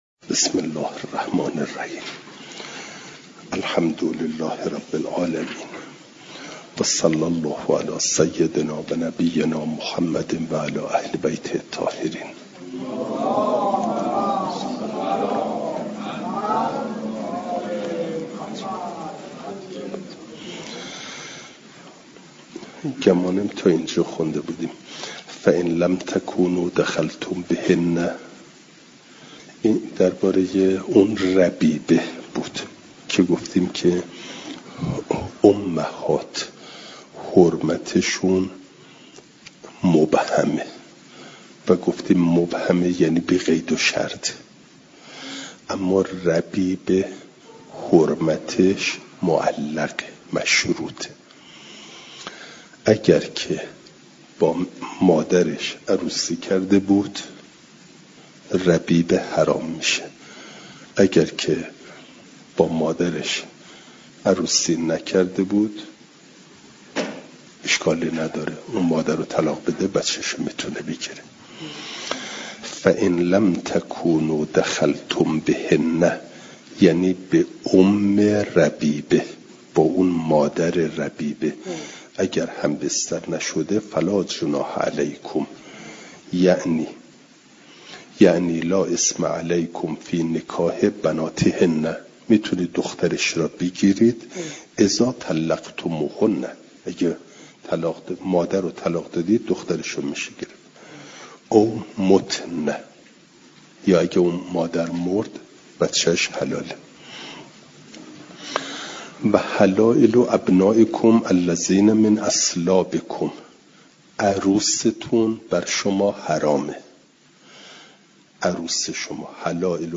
جلسه سیصد و پنجاه و دوم درس تفسیر مجمع البیان